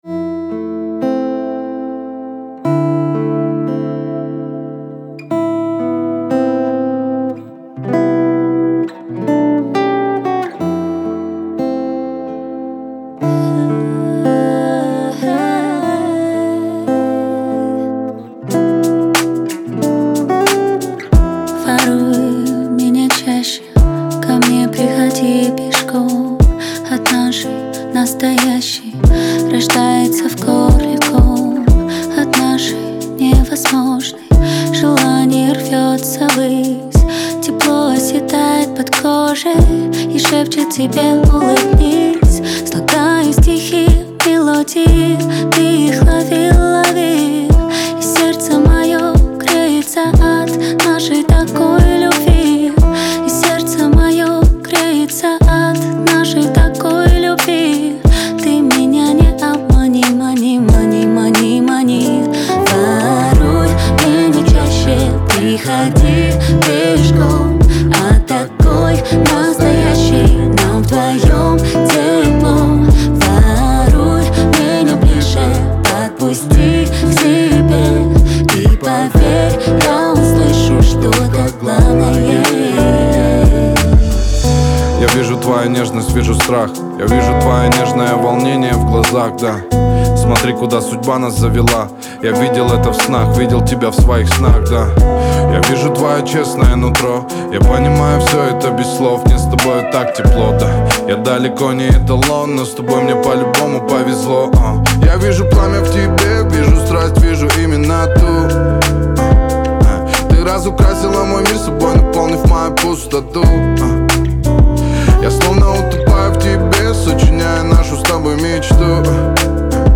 это яркая и мелодичная композиция в жанре хип-хоп и R&B